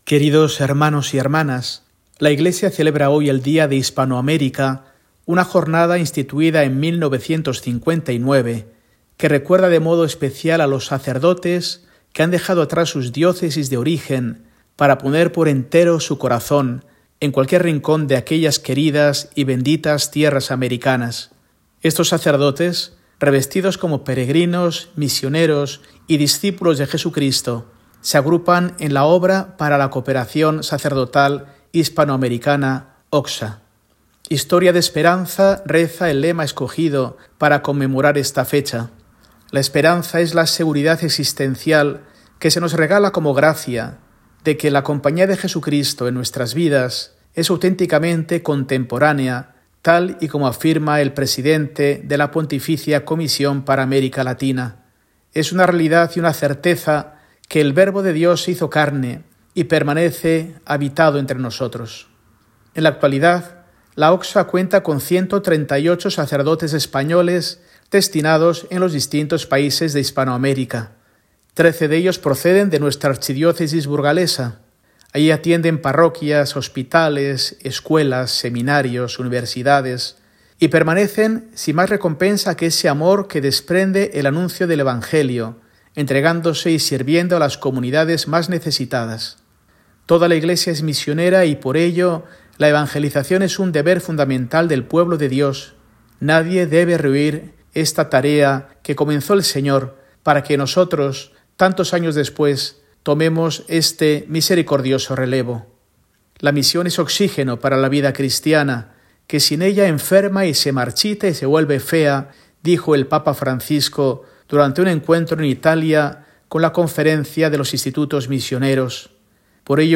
Mensaje semanal de Mons. Mario Iceta Gavicagogeascoa, arzobispo de Burgos, para el domingo, 2 de marzo de 2025, VIII del Tiempo Ordinario